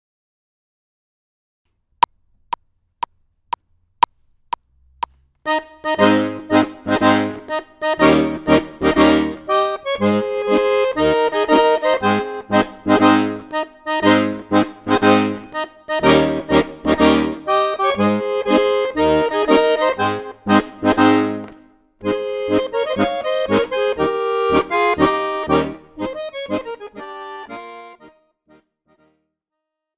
Besetzung: Tuba